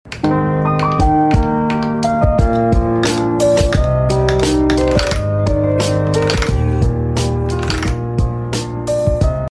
This Pure White Keyboard Is Sound Effects Free Download